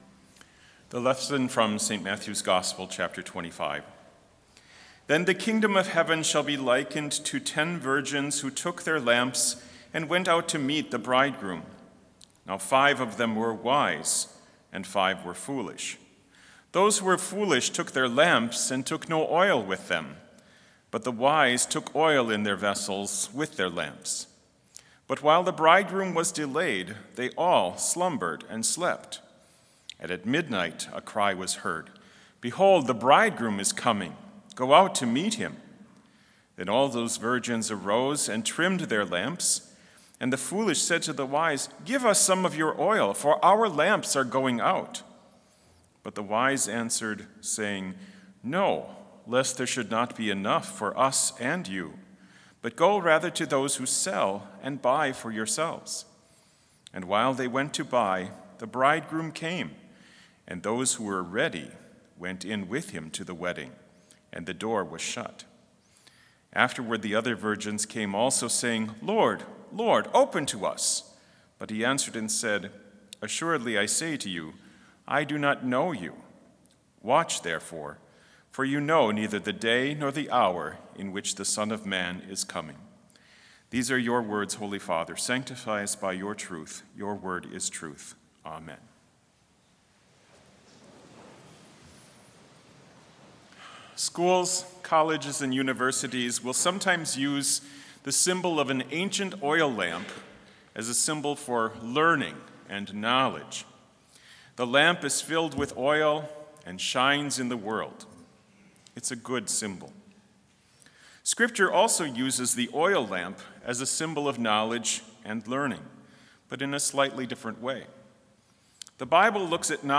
Complete Service
Handbell choir
• Hymn 544 - Wake, Awake, For Night is Flying View
This Chapel Service was held in Trinity Chapel at Bethany Lutheran College on Monday, December 5, 2022, at 10 a.m. Page and hymn numbers are from the Evangelical Lutheran Hymnary.